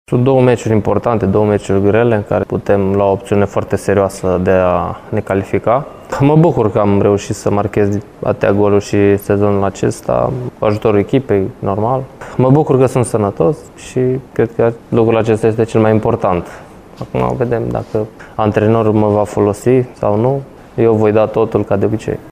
Îl ascultăm pe atacantul lui CFR Cluj, arădeanul George Ţucudean.